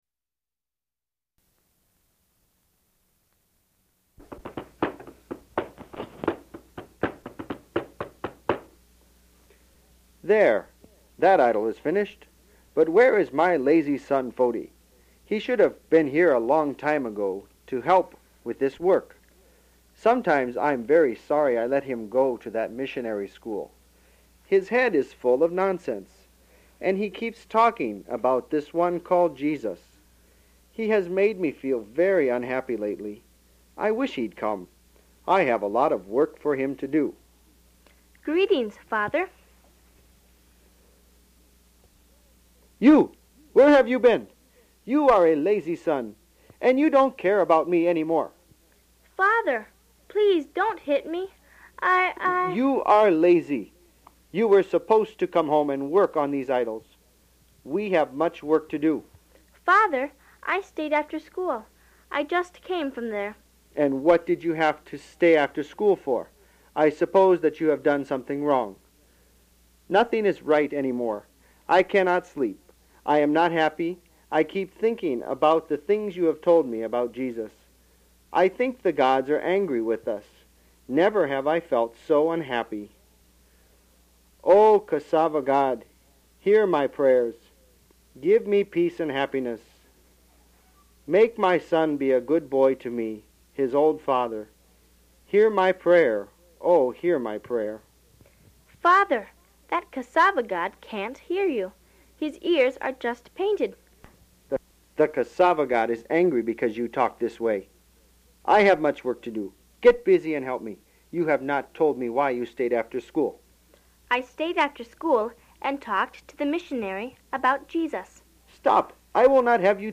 * Monso’s Idol Shop (Puppet Show)
puppet-show.mp3